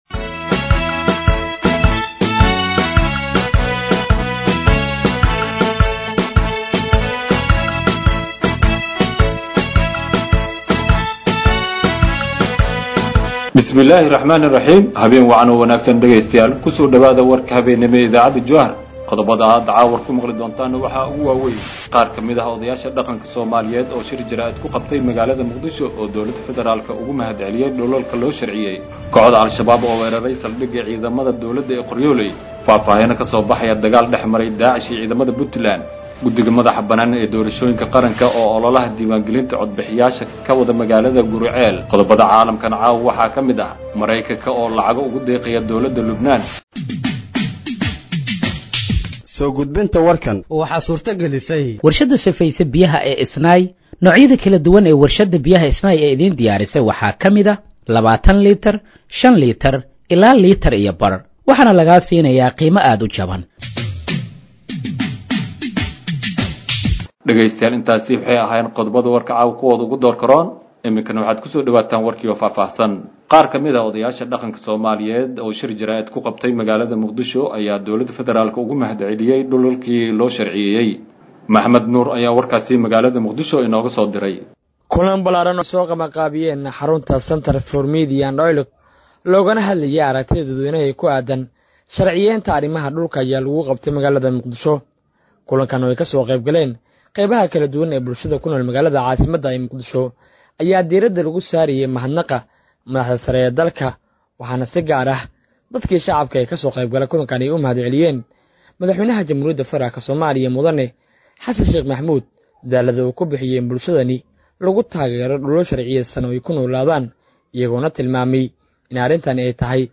Dhageeyso Warka Habeenimo ee Radiojowhar 03/10/2025